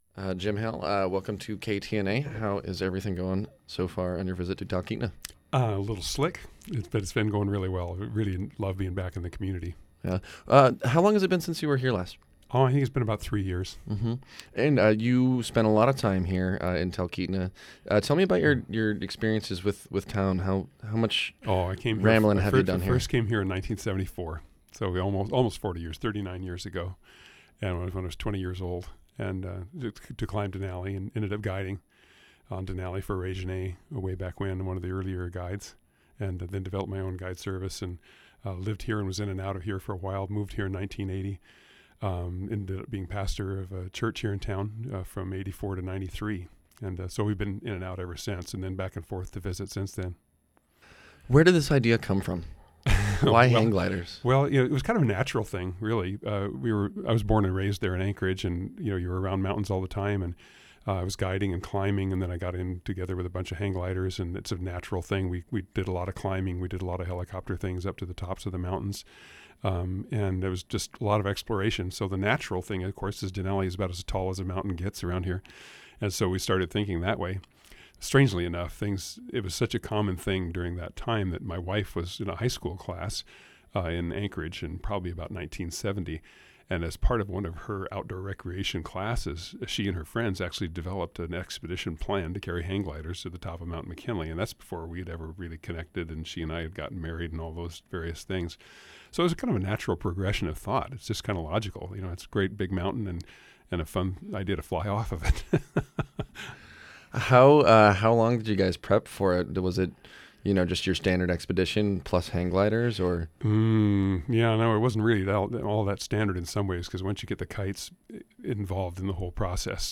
listen to short interview